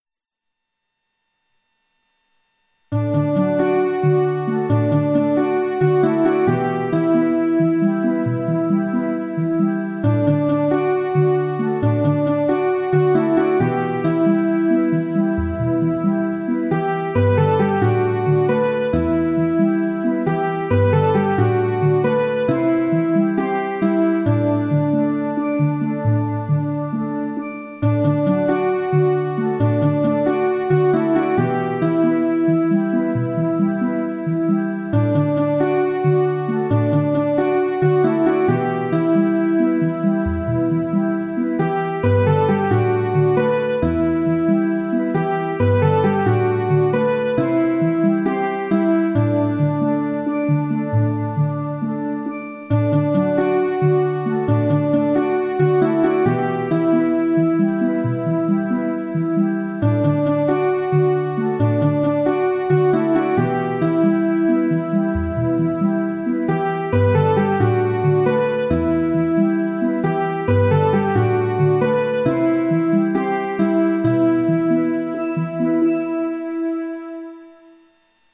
An energetic praise and worship.